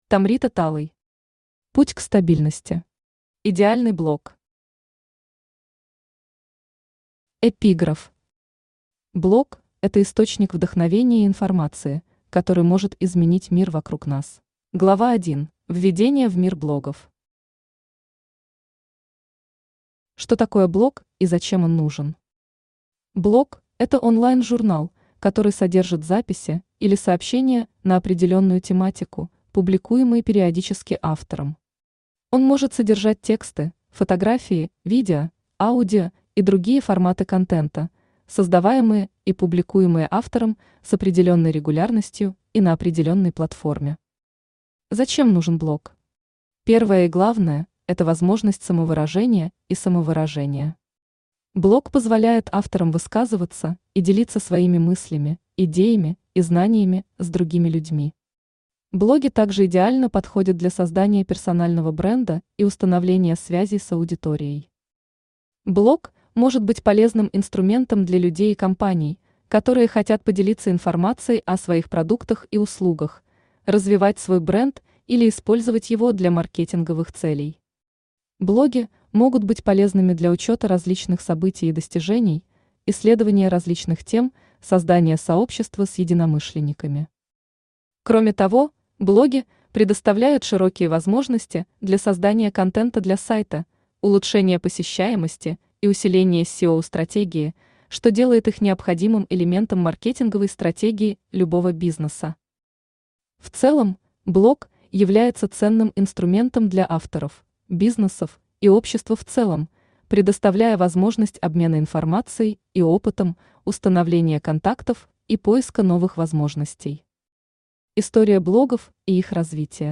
Аудиокнига Путь к стабильности. Идеальный блог | Библиотека аудиокниг
Идеальный блог Автор Tomrita Talay Читает аудиокнигу Авточтец ЛитРес.